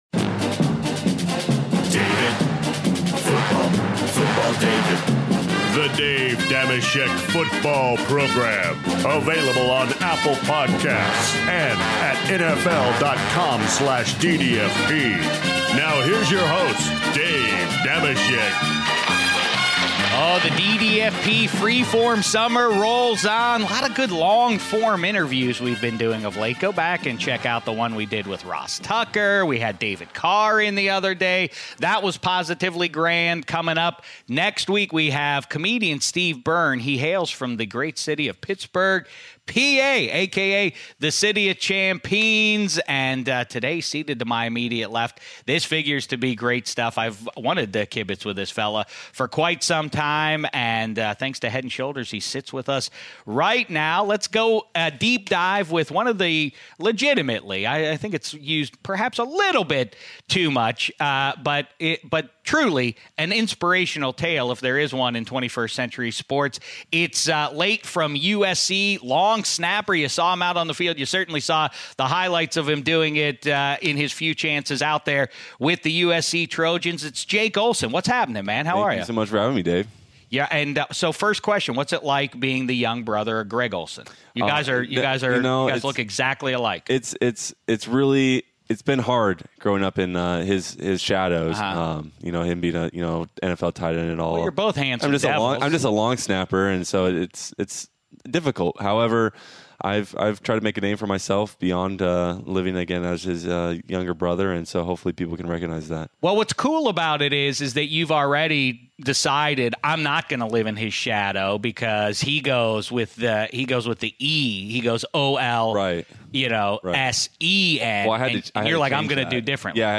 is joined in Studio 66